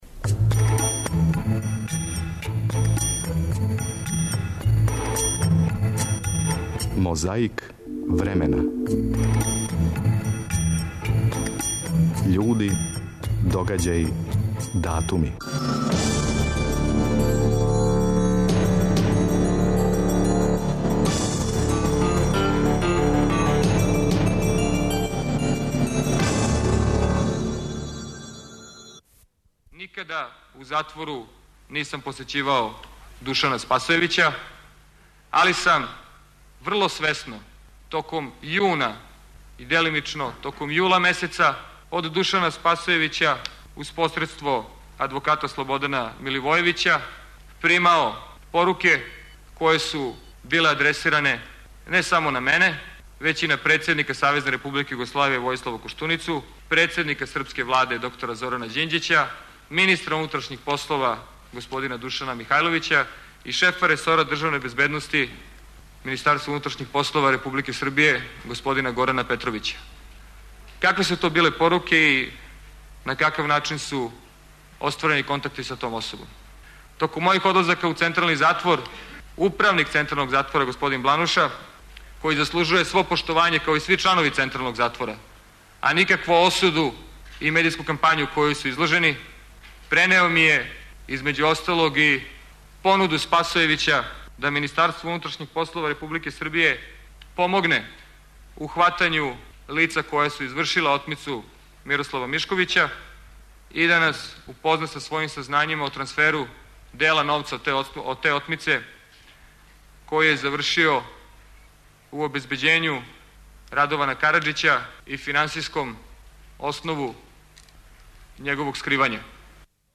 Крећемо тонским записом са сведочења Чедомира Јовановића, на заједничкој седници Одбора за правосуђе и управу и Одбора за одбрану и безбедност Скупштине Србије.
Користећи мегафон говорио је Драган В еселинов. 19. јуна 1999. године, у Алексинцу је обележен почетак радова на обнови наше земље после НАТО бомбардовања.